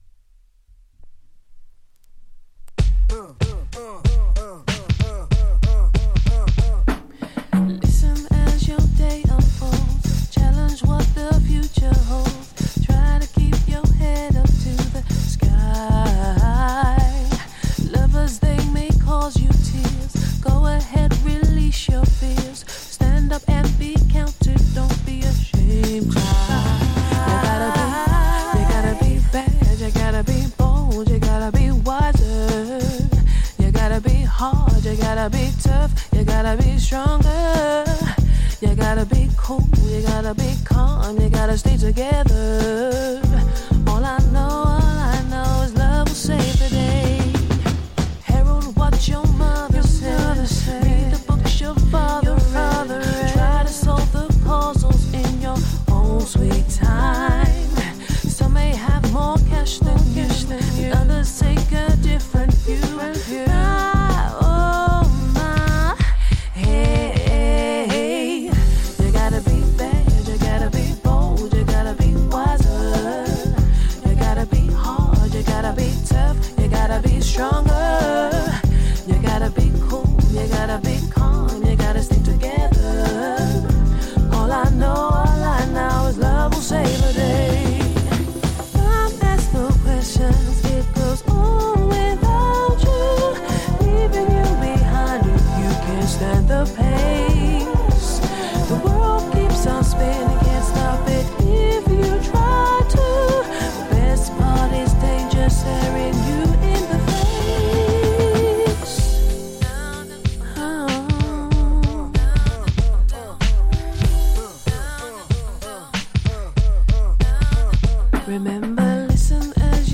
イギリスのフィメール・ソウル・シンガー
ジャンル(スタイル) SOULFUL HOUSE / UK SOUL / R&B